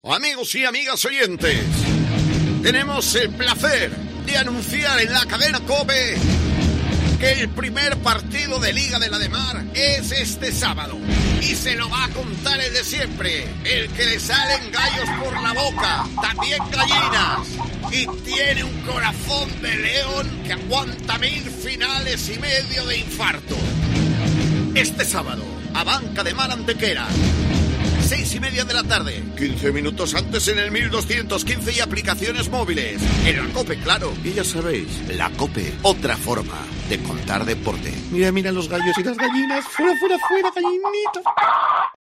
Escucha la cuña promocional del partido Abanca Ademar-Antequera el día 11-09-21 a las 18:30 h en el 1.215 OM